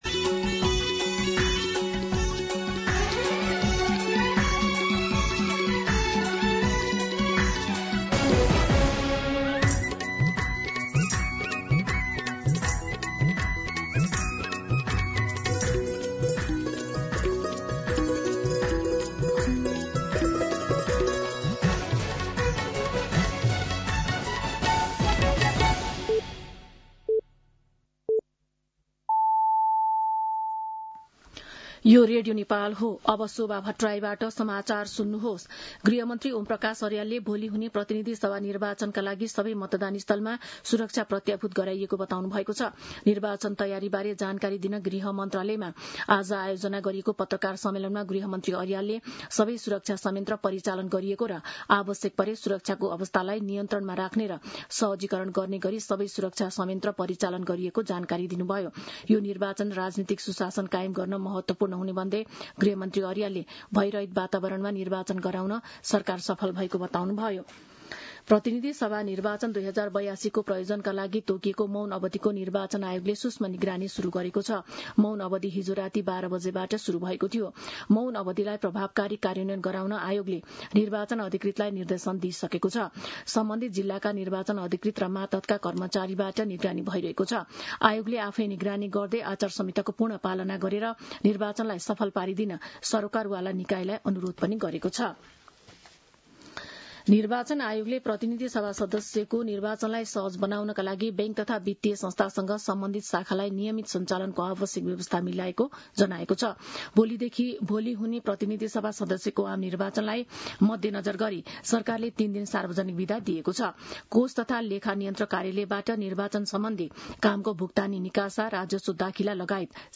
मध्यान्ह १२ बजेको नेपाली समाचार : २० फागुन , २०८२